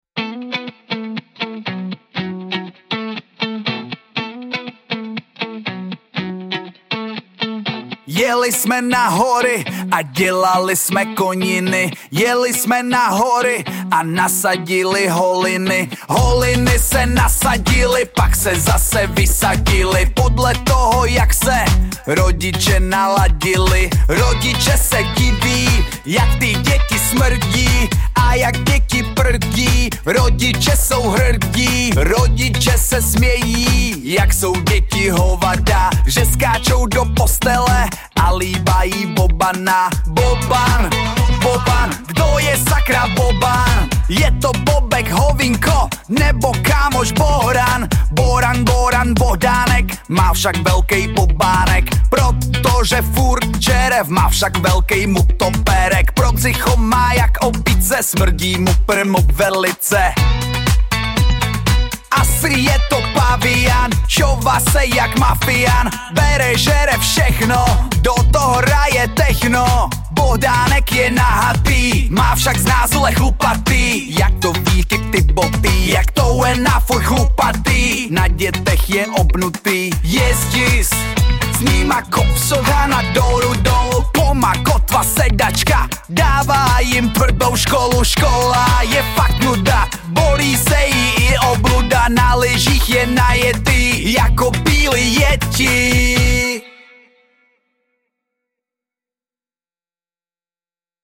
Collaborative lyric writing with short, punchy hooks.
Generated track
Ready-to-play MP3 from ElevenLabs Music.